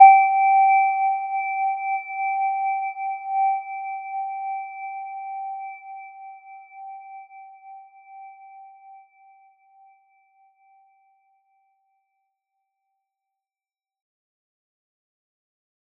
Gentle-Metallic-1-G5-mf.wav